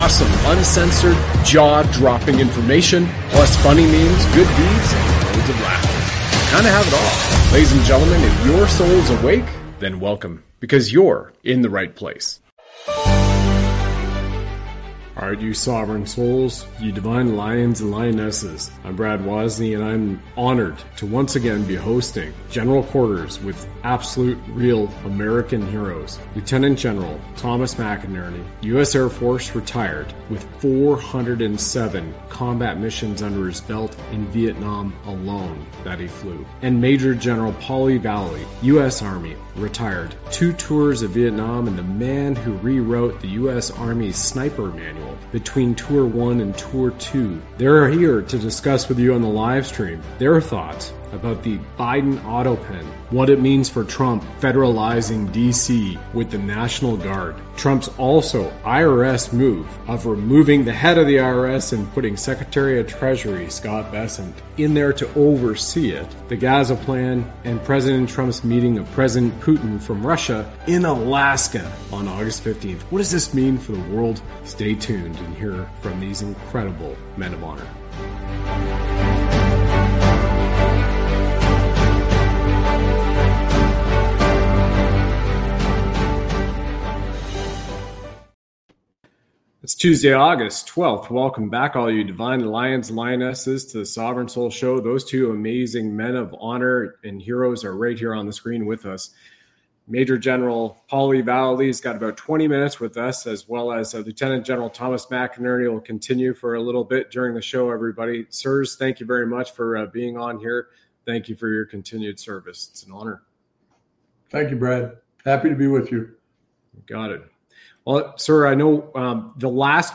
They explore President Trump's actions, a meeting with Putin, and the situation in Gaza. The officers share their experiences, discuss disagreements, and highlight the importance of peace and economic growth in places like Ukraine and Gaza.